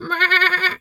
sheep_2_baa_04.wav